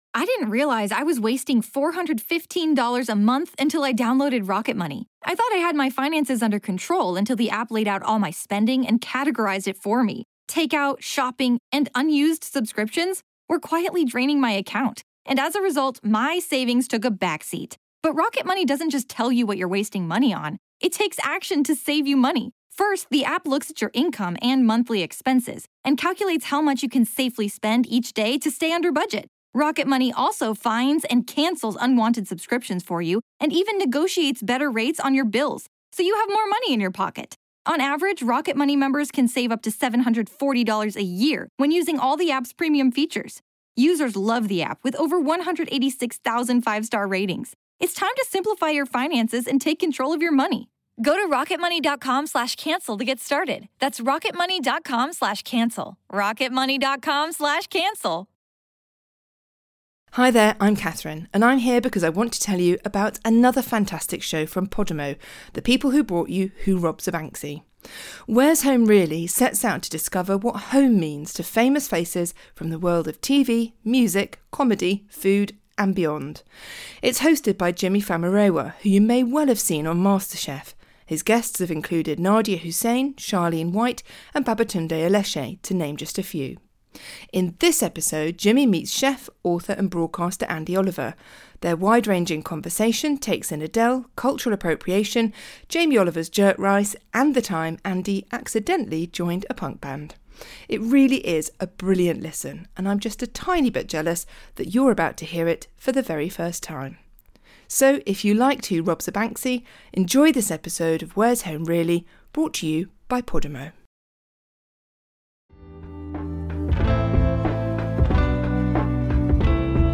Where's Home Really? sets out to discover what home means to famous faces from the worlds of TV, music, comedy, food and beyond. In this episode, Jimi Famurewa meets chef, author and broadcaster Andi Oliver to discuss Adele, Jamie Oliver’s jerk rice and the time Andi accidentally joined a punk band.